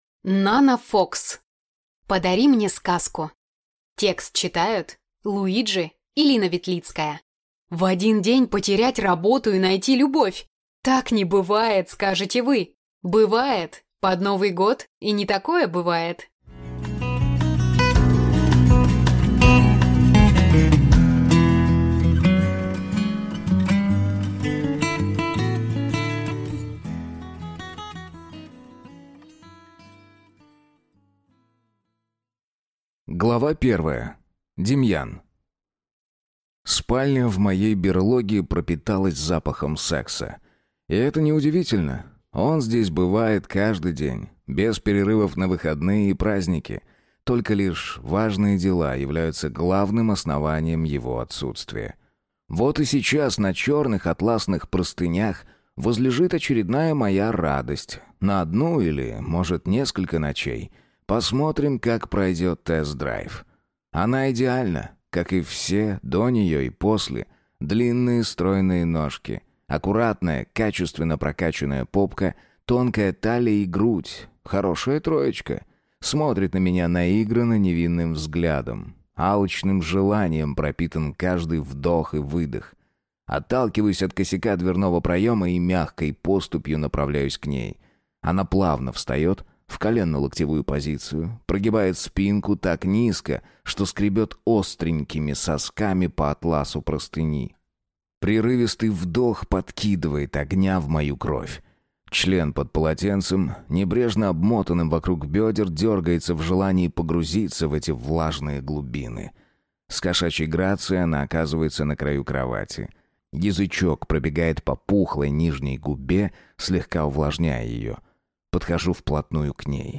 Аудиокнига Подари мне сказку | Библиотека аудиокниг